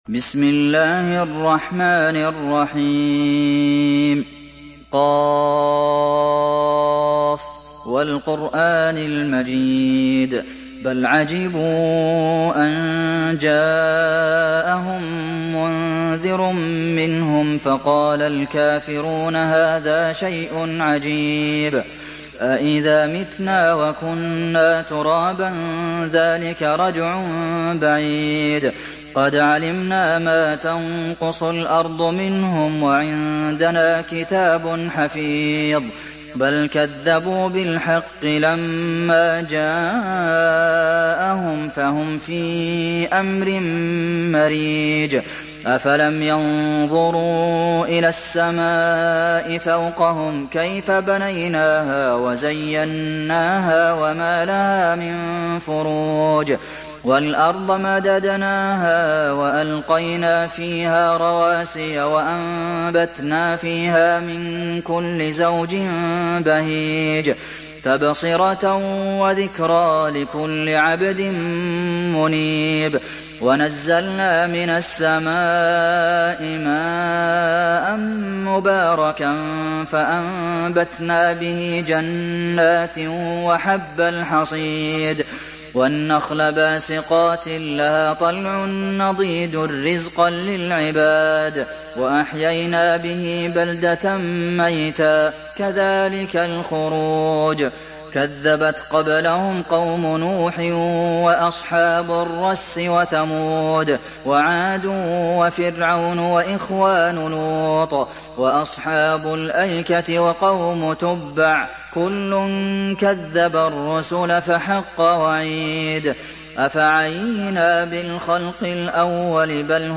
Kaf Suresi İndir mp3 Abdulmohsen Al Qasim Riwayat Hafs an Asim, Kurani indirin ve mp3 tam doğrudan bağlantılar dinle